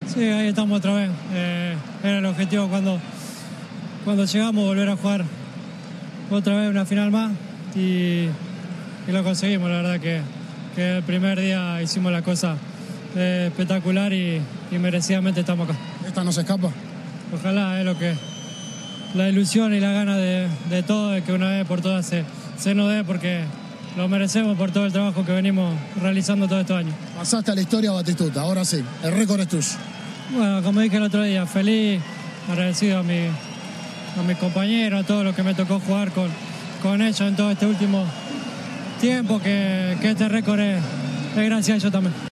"La Pulga" en declaraciones después del partido que los llevo a la final de la Copa América